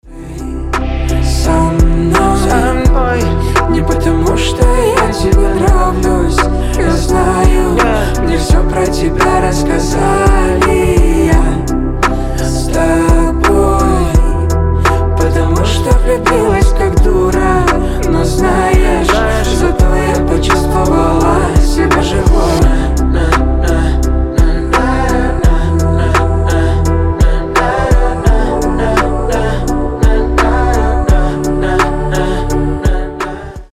• Качество: 320, Stereo
мужской голос
женский голос
спокойные